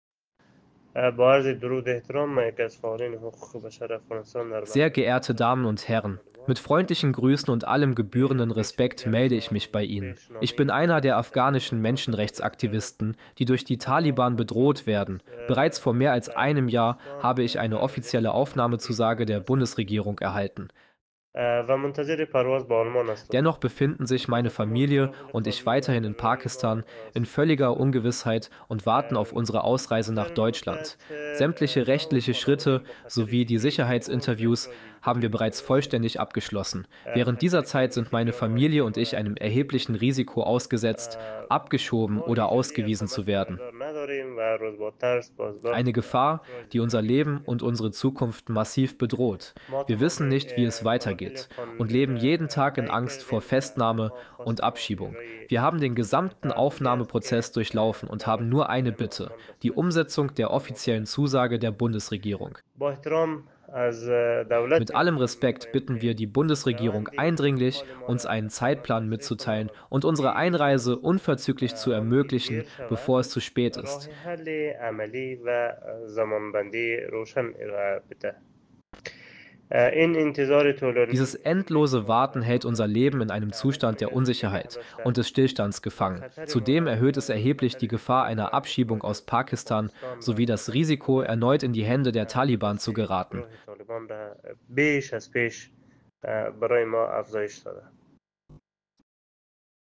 Voice_2_menschenrechtsaktivist.mp3